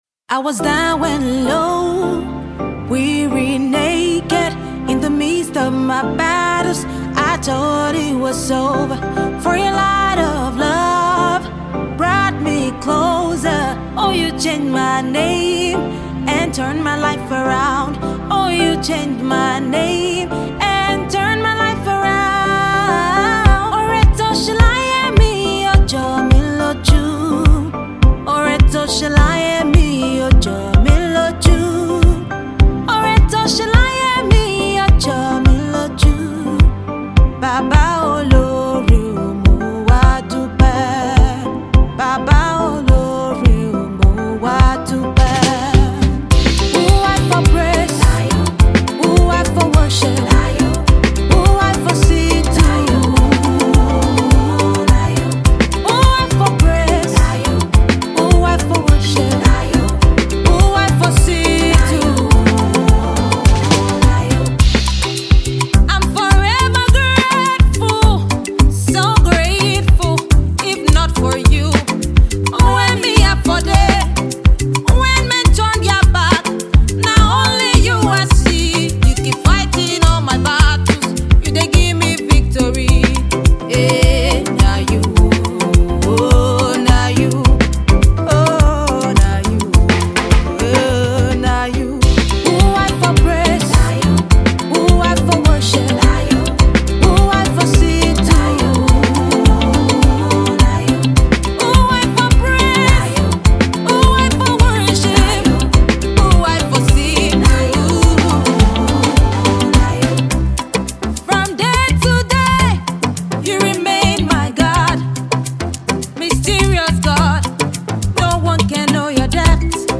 powerful praise song